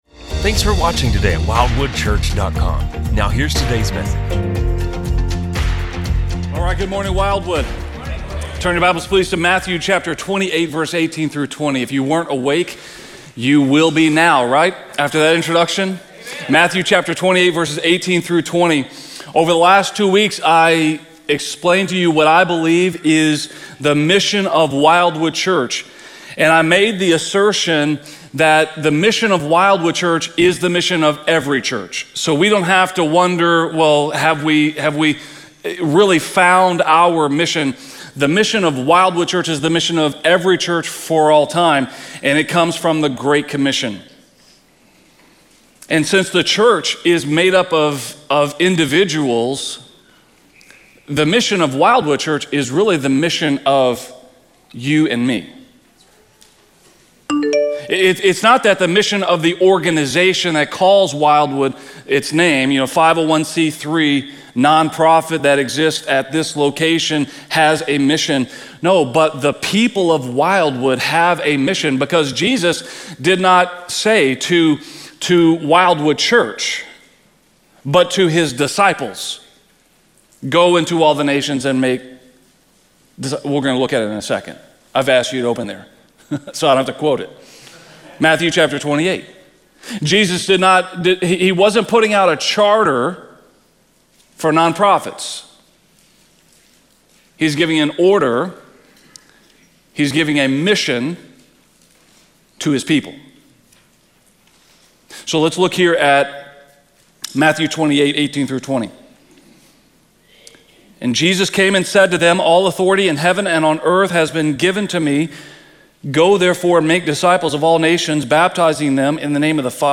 A message from the series "The Way Forward." Families have huge impact on our communities.